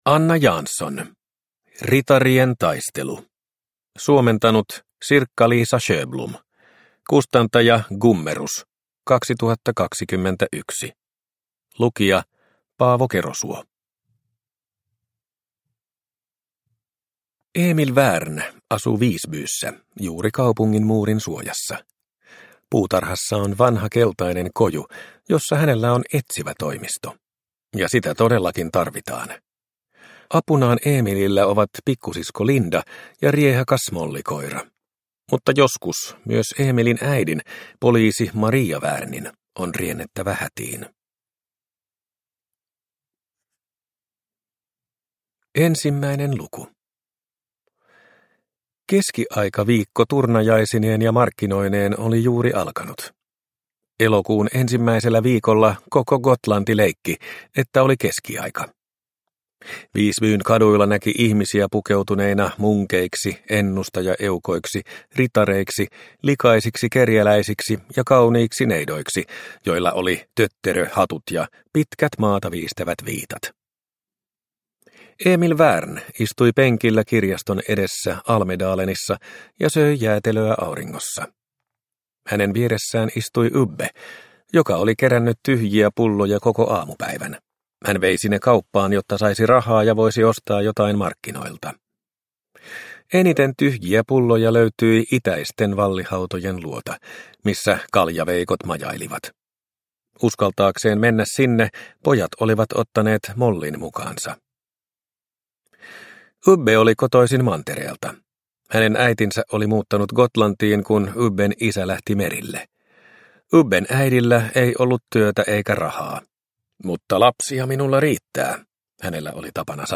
Ritarien taistelu – Ljudbok – Laddas ner